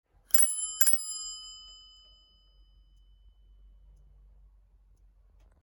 Разные велосипедные звуки: колеса и трещотки велосипедные, езда на велосипеде, звонок, тормоза, цепи.
5. Два прокрута звонящей ручки
zvonok-velosipeda-3.mp3